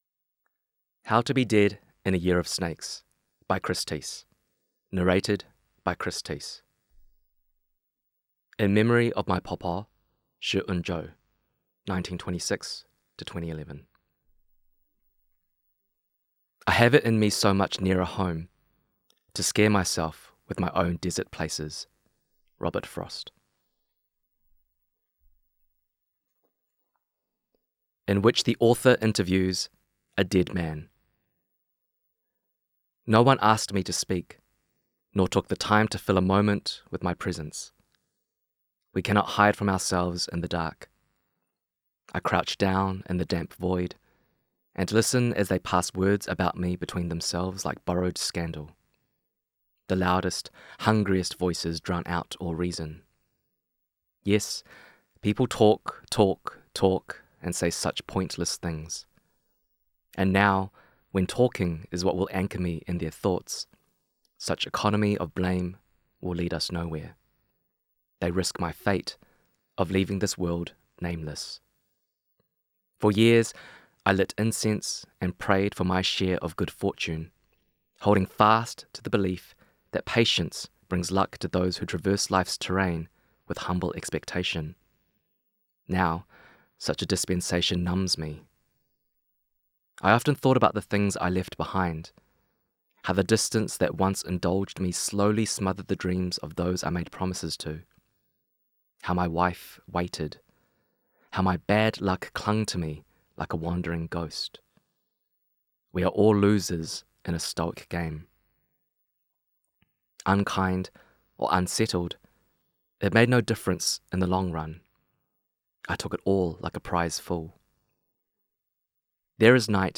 Narrated by: Chris Tse
Format: Digital audiobook